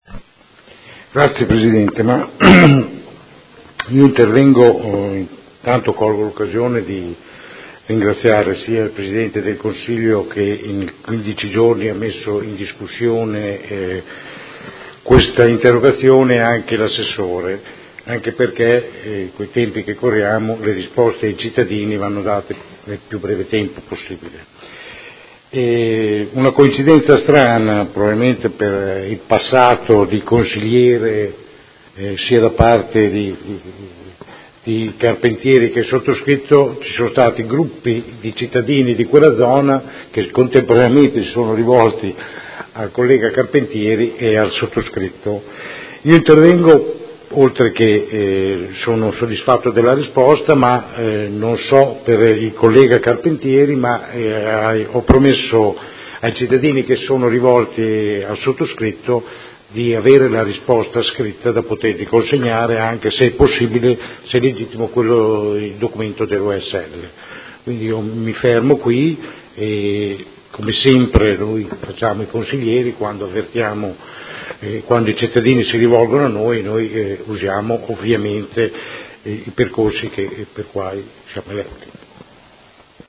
Seduta del 8/06/2017 Interrogazione dei Consiglieri Carpentieri (PD) e Rocco (Art.1-MDP) avente per oggetto: Miasmi e cattivi odori a Modena Est. Trasformata in interpellanza su richiesta del Consigliere Stella.